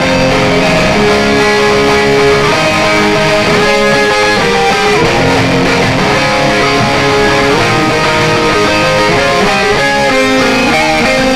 さらにNLS Buss本体のDRIVEも12上げてやるぜ！歪み最高！
要らないと思いますが設定後のサウンドです。